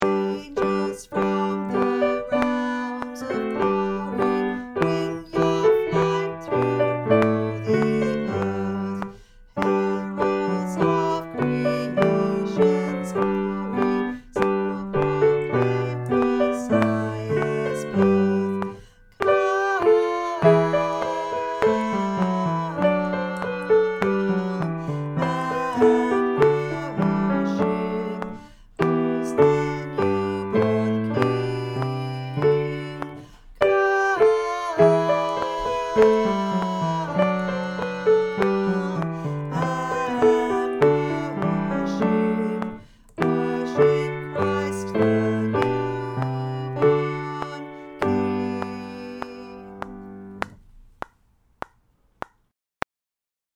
Vox Populi Choir is a community choir based in Carlton and open to all comers.
Angels_from_the_Realms-Tenor.mp3